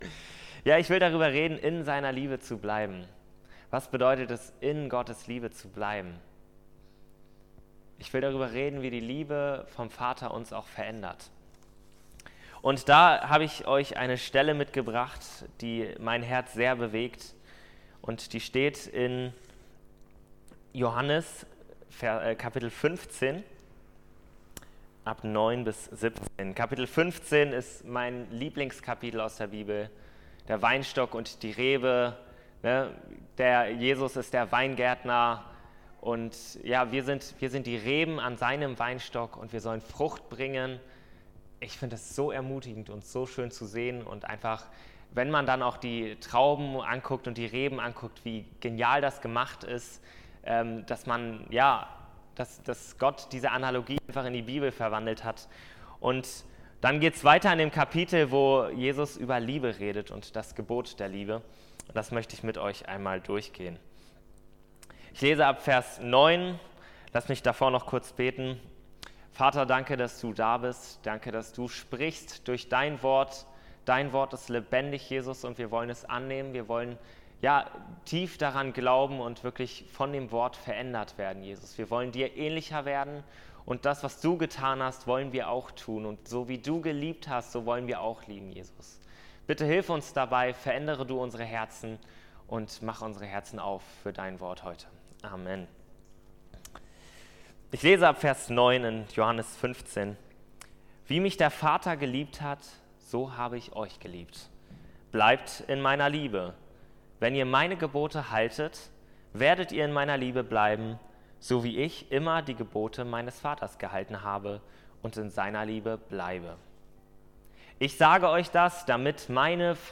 Dienstart: Predigt